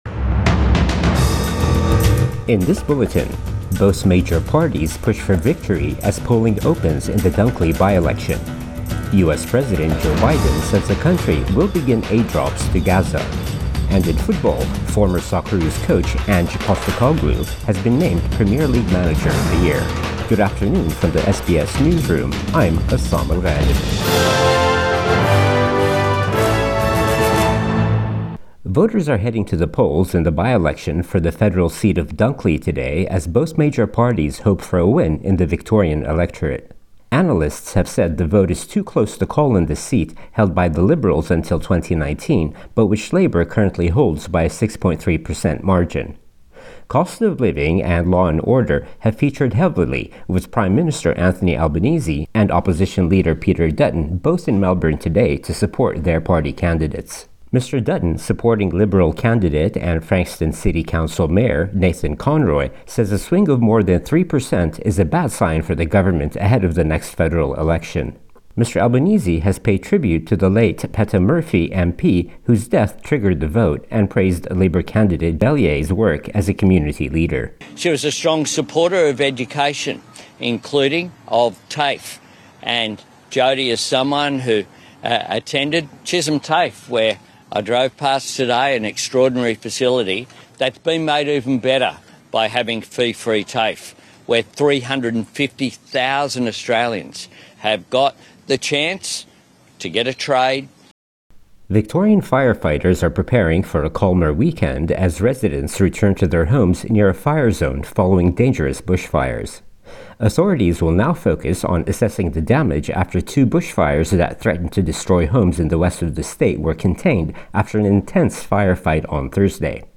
Midday News Bulletin 2 March 2024